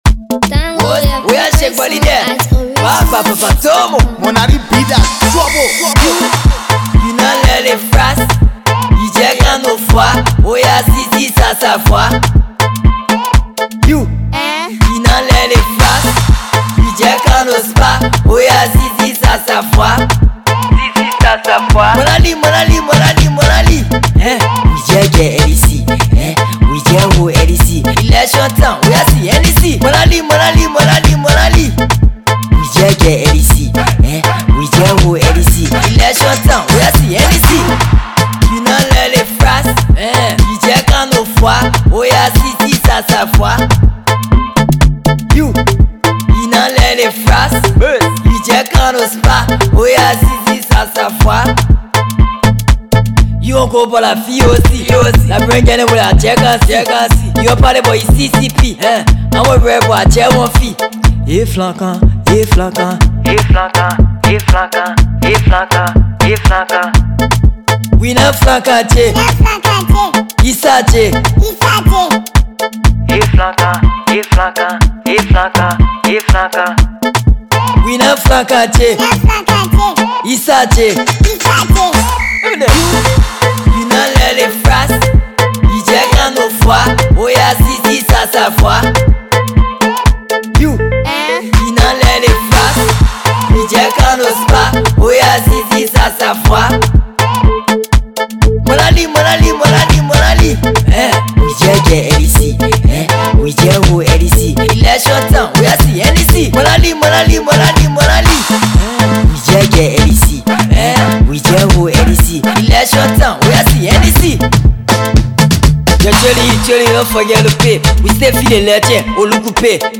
infectious and danceable track